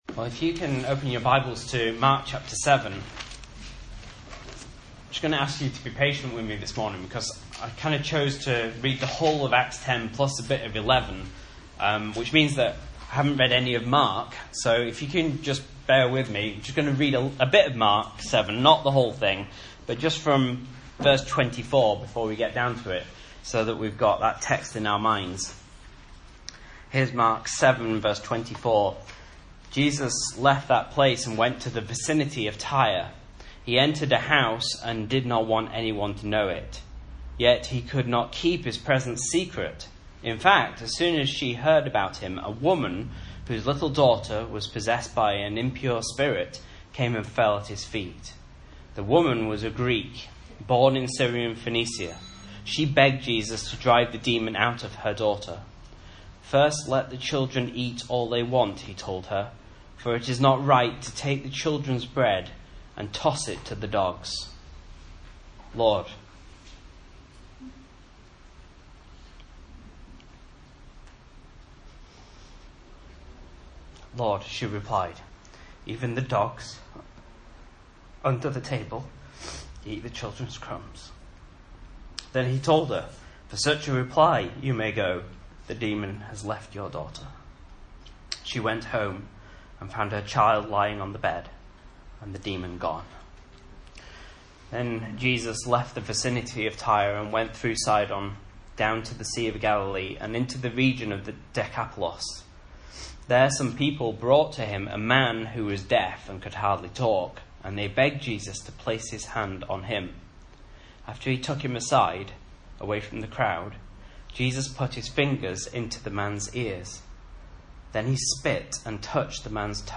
Message Scripture: Mark 7:24-37 | Listen